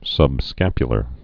(sŭb-skăpyə-lər) Anatomy